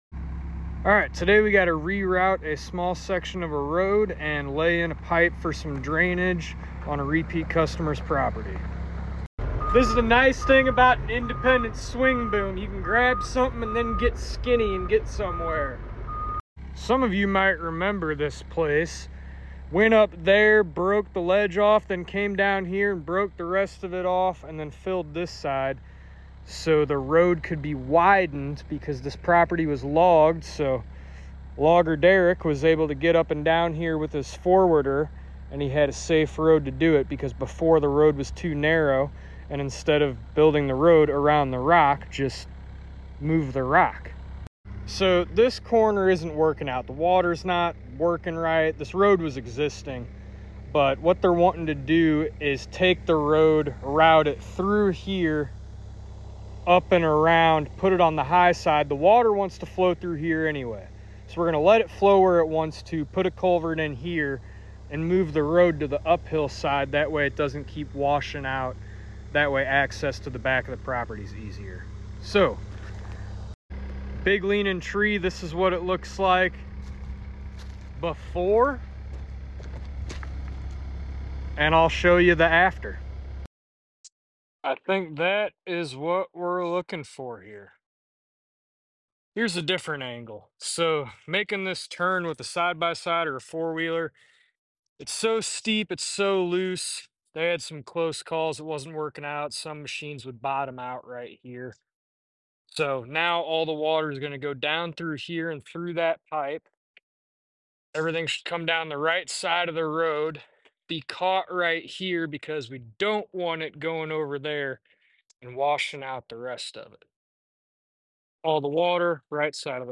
Little road job this afternoon#heavyequipment